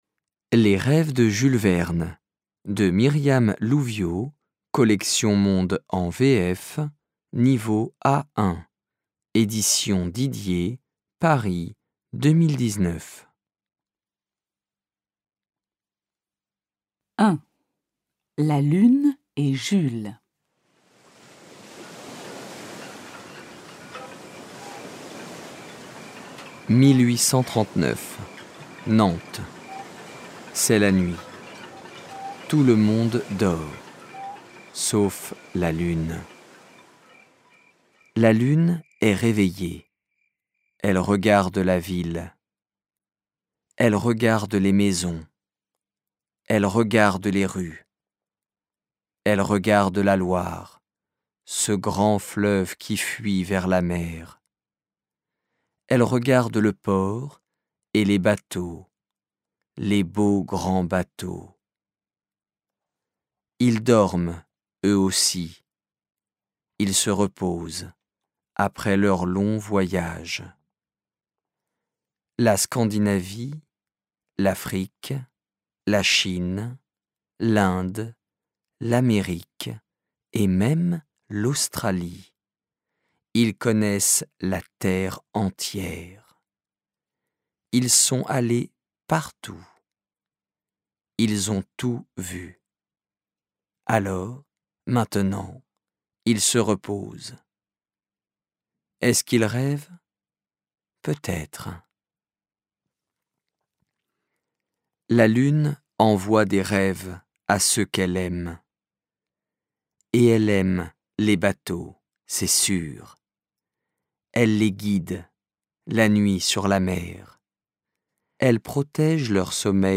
Lecture simplifiée: Les romans de Jules Verne